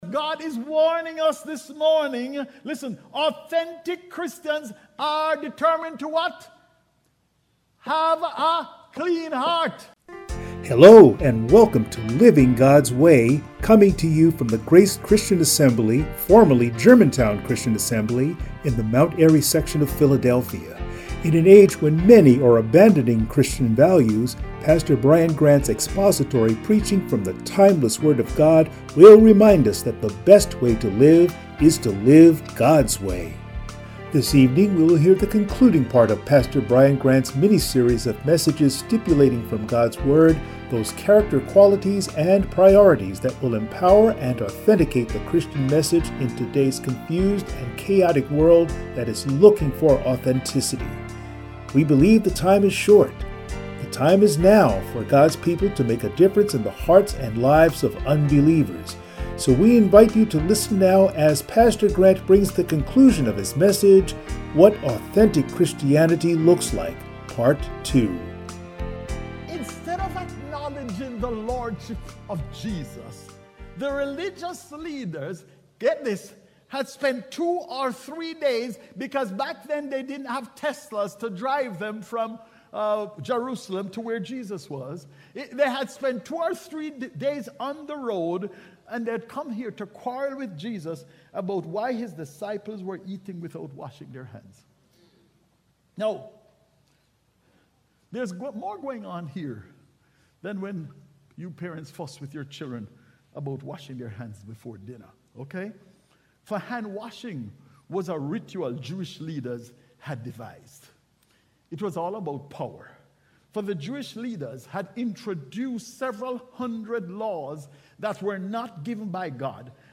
Passage: Matthew 15:1-20 Service Type: Sunday Morning